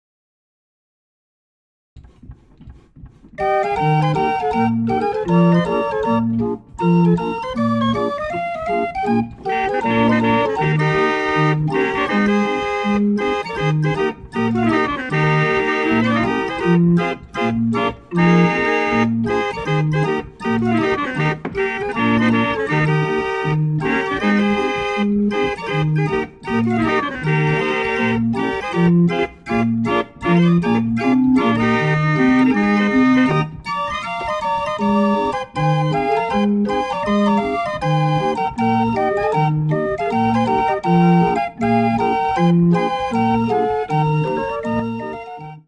Realisierte 31+4er Drehorgel
Somit ist eine 35er Drehorgel entstanden.
In seinem Fall verwendet er aufschlagende Zungen.
Hörprobe mit Trompeten